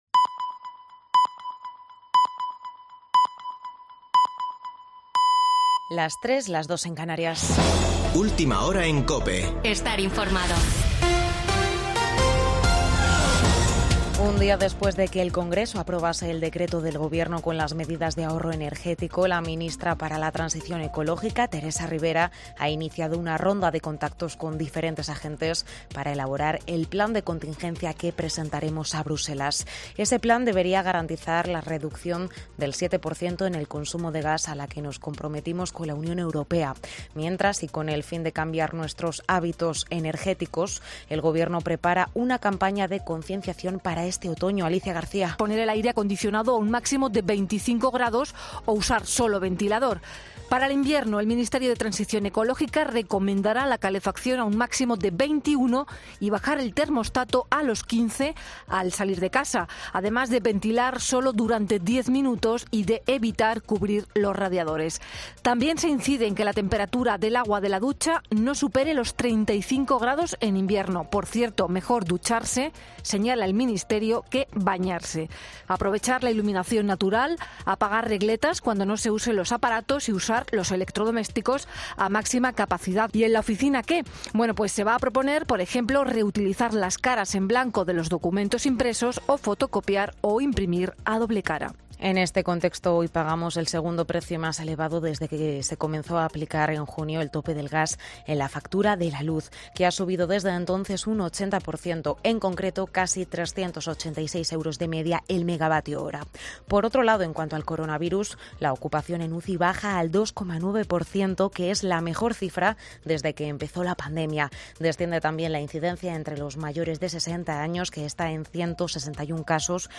Boletín de noticias de COPE del 27 de agosto de 2022 a las 03.00 horas